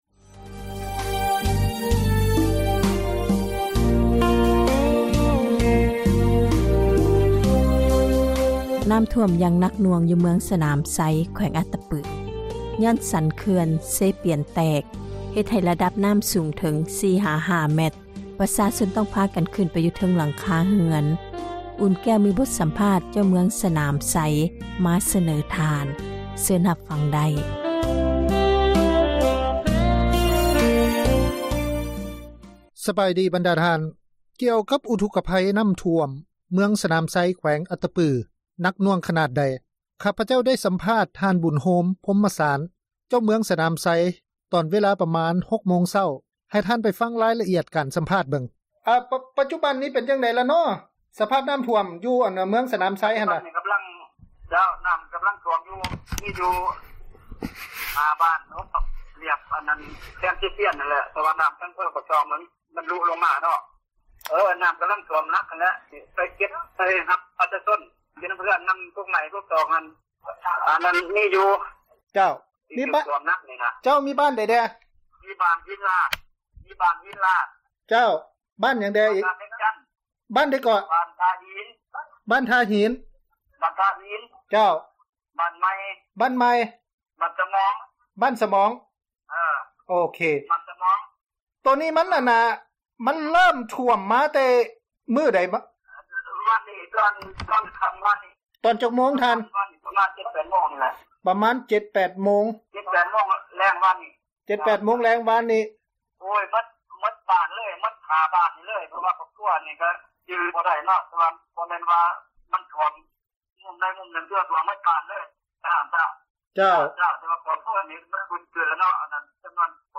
ສຳພາດທ່ານເຈົ້າເມືອງ ສະໜາມໄຊ
ກ່ຽວກັບເຫດການນ້ຳຖ້ວມ ເມືອງສະໜາມໄຊ ແຂວງອັດຕະປື ຢ່າງໜັກໜ່ວງ ເອເຊັຽເສຣີ ໄດ້ຂໍສຳພາດ ທ່ານ ບຸນໂຮມ ພົມມະສານ ເຈົ້າເມືອງ ເມືອງສະໜາມໄຊ ຕອນເວລາ ປະມານ 6 ໂມງເຊົ້າ ຂອງມື້ນີ້. ທ່ານ ບຸນໂຮມ ພົມມະສານ ກ່າວວ່າສາເຫດ ທີ່ເຮັດໃຫ້ ນ້ຳຖ້ວມໜັກ ແມ່ນເປັນຍ້ອນ ສັນເຂື່ອນເຊປຽນ ແຕກ.